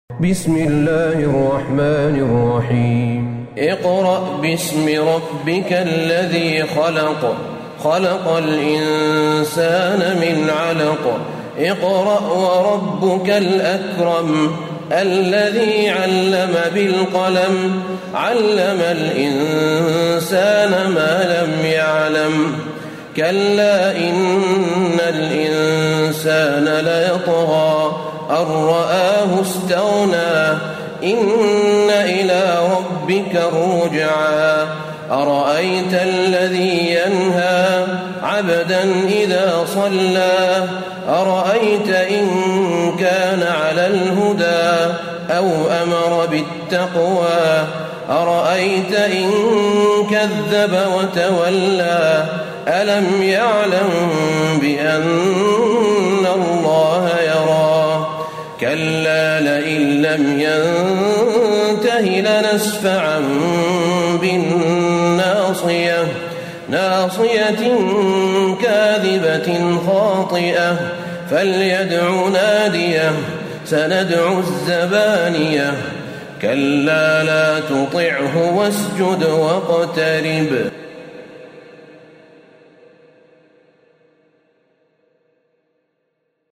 سورة العلق Surat Al-Alaq > مصحف الشيخ أحمد بن طالب بن حميد من الحرم النبوي > المصحف - تلاوات الحرمين